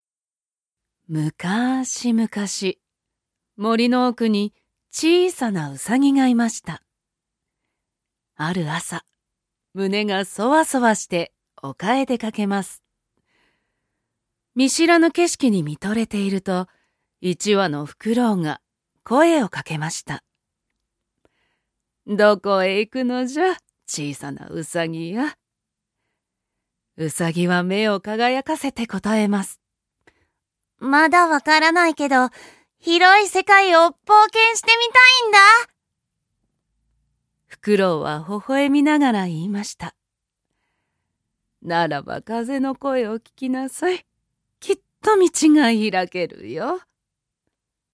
◆朗読ナレーション◆